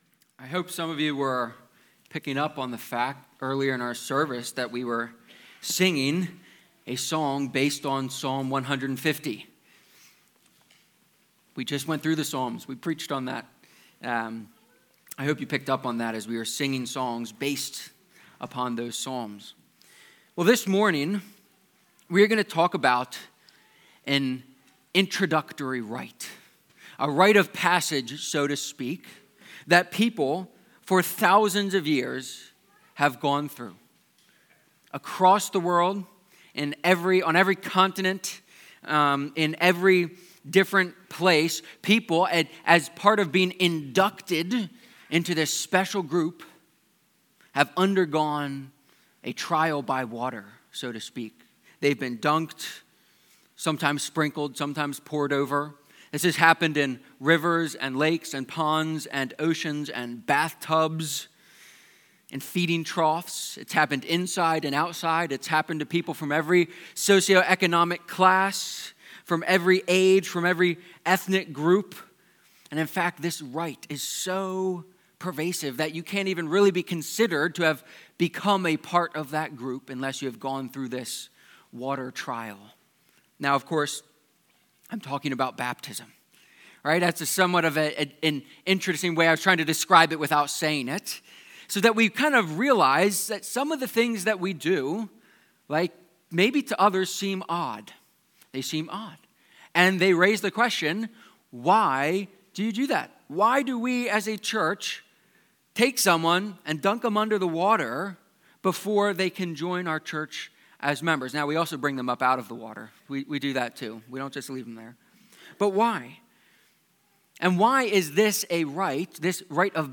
Why-baptism-sermon.mp3